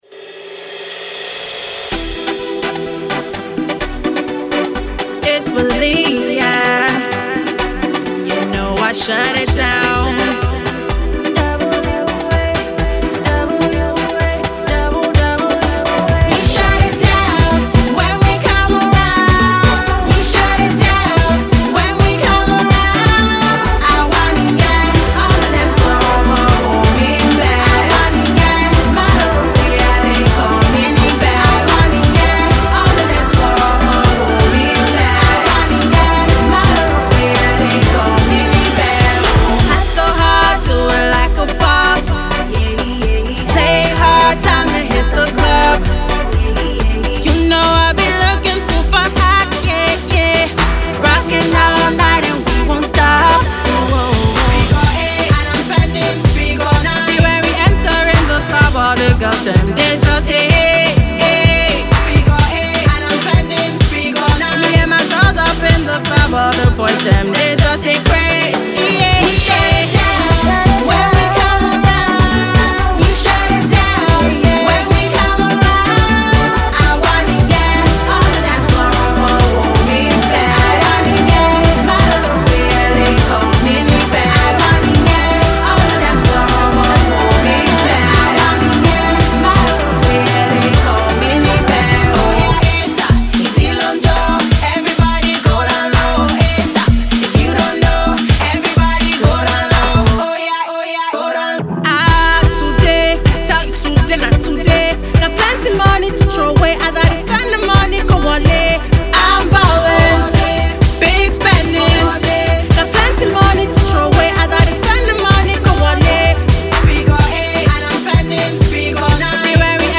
Uber-talented songstress
specially crafted to rock the dance floor